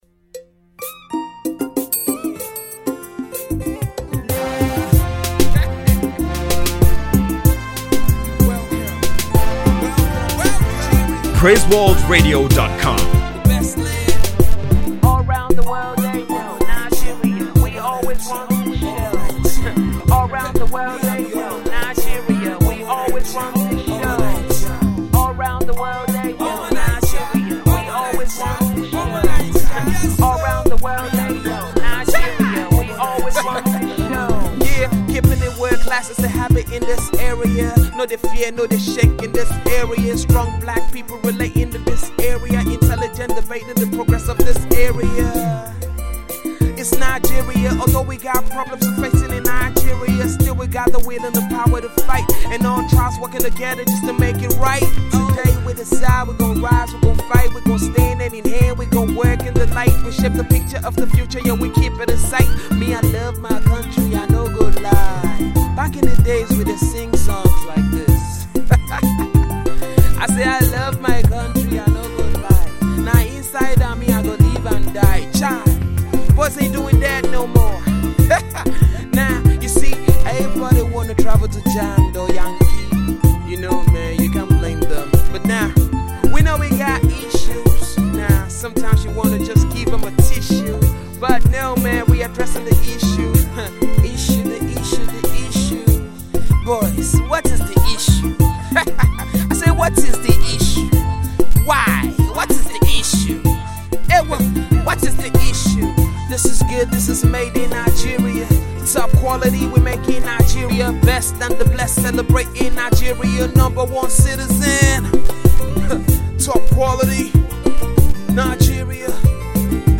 Nigerian Gospel rapper